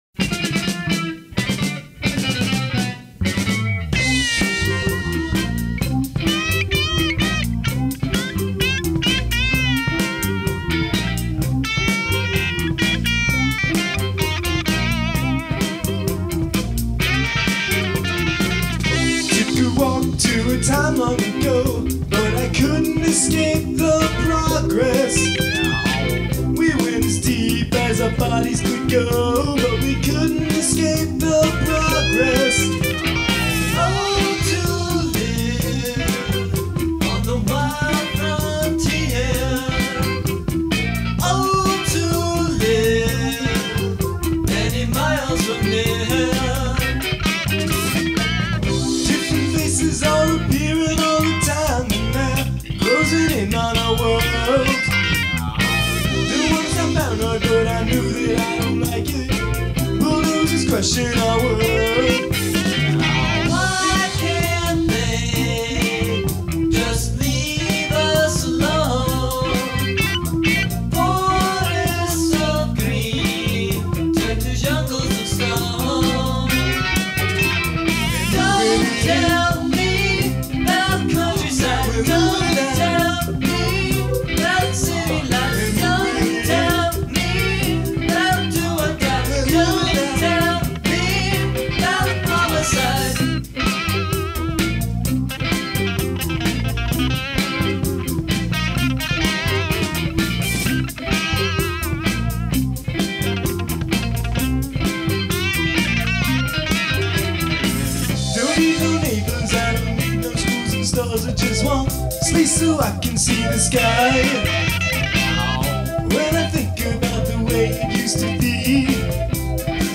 During that weekend the group recorded eleven songs on the Tascam 22-4, 4-track, reel-to-reel, tape recorder.
percussion
bass guitar
keyboards
guitar, vocals